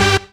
Synths
ED Synths 07.wav